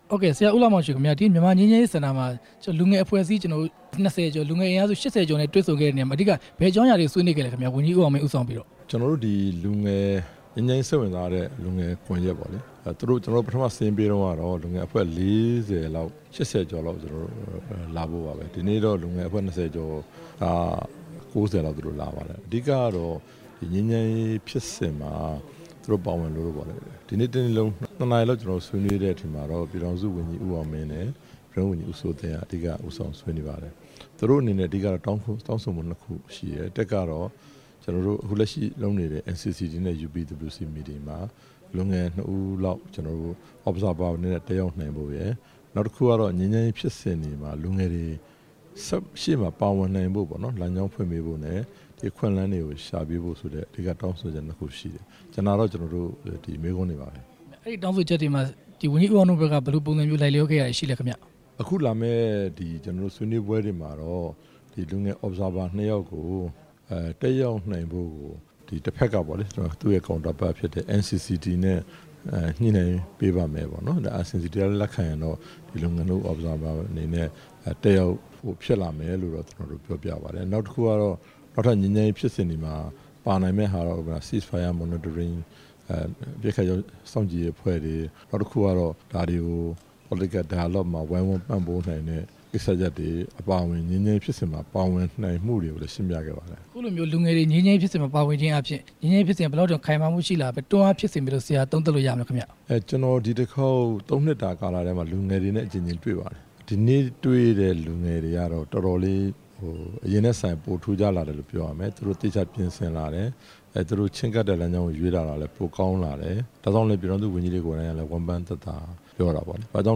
UPWC နဲ့ လူငယ်အဖွဲ့ ၂ဝ ကျော် တွေ့ဆုံတဲ့အကြောင်း မေးမြန်းချက်